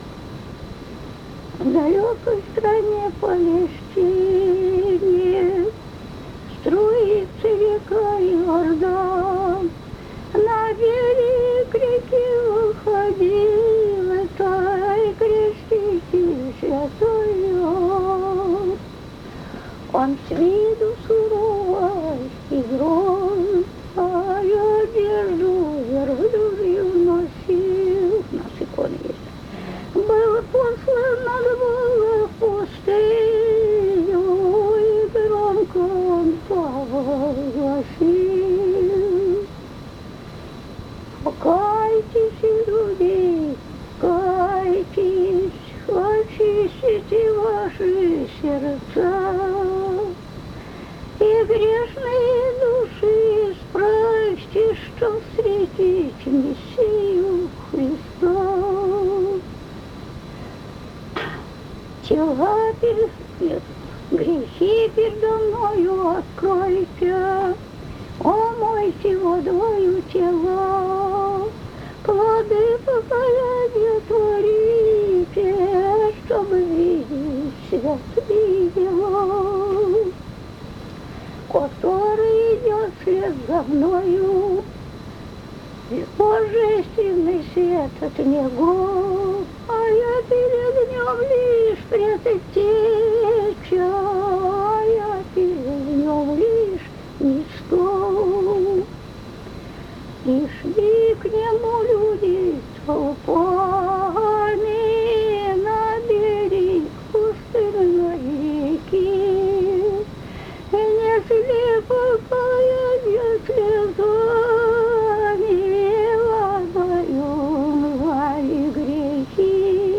Духовные стихи в фольклорной традиции Селивановского района Владимирской области
Духовный стих